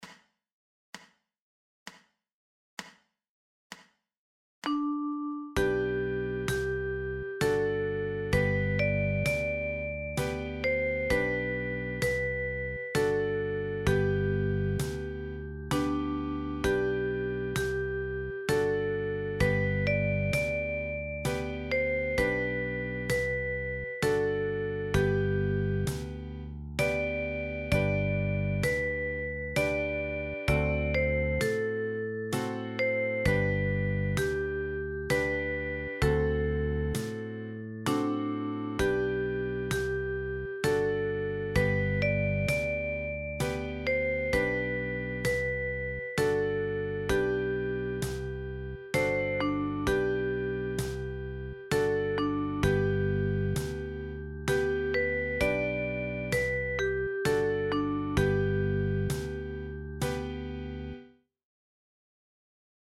eBook Weihnachtslieder für die Okarina + Sounds